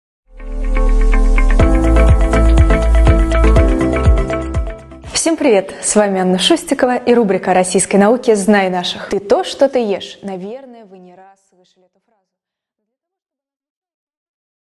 Аудиокнига О диете и генетике | Библиотека аудиокниг